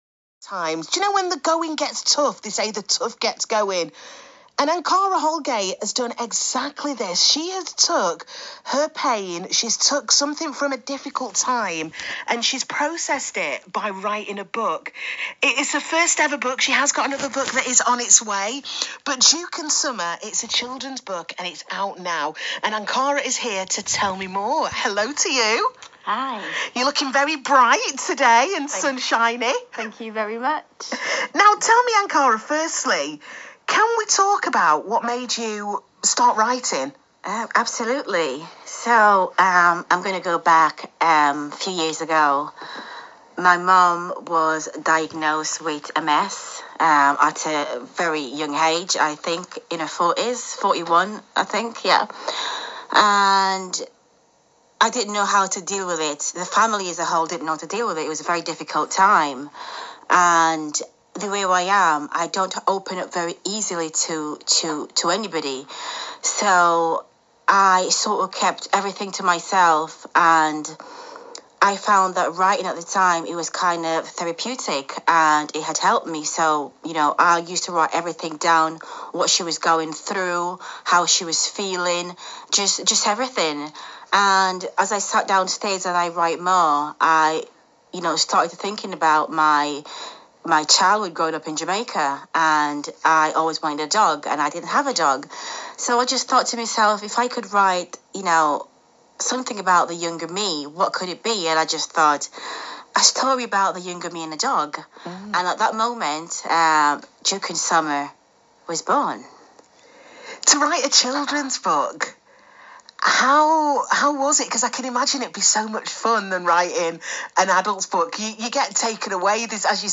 interview
on BBC Radio Manchester